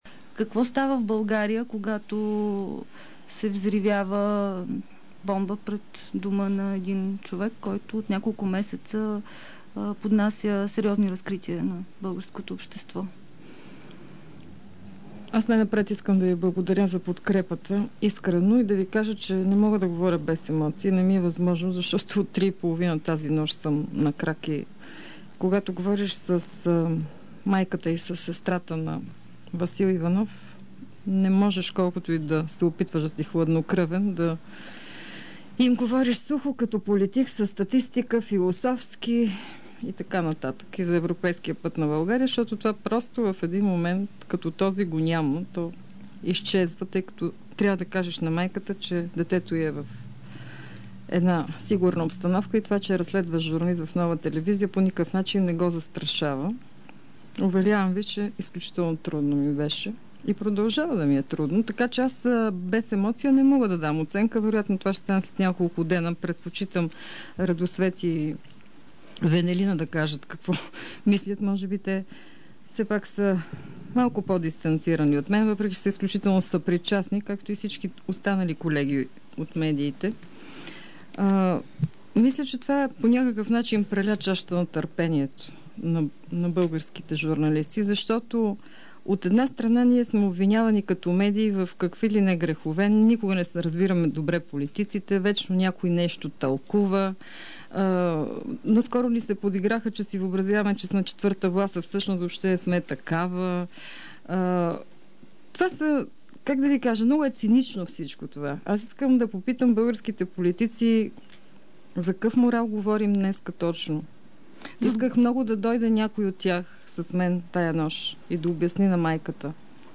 Дебат